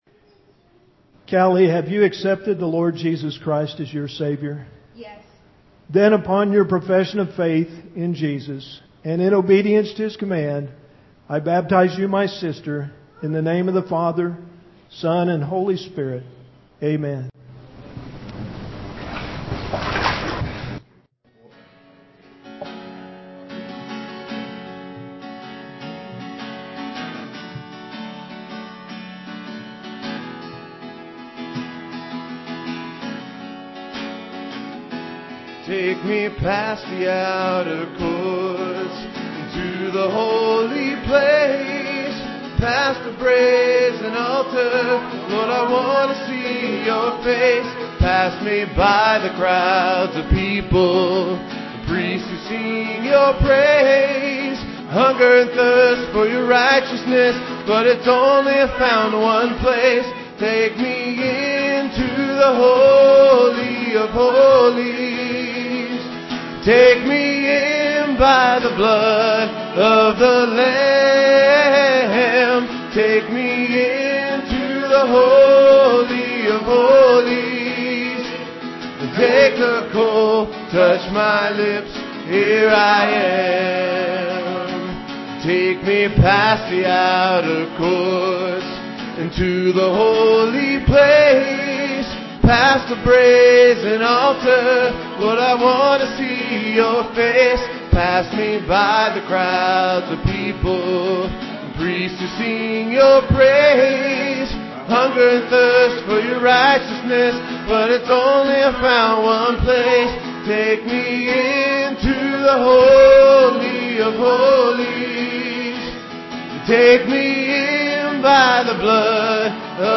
Guitar
Piano
Organ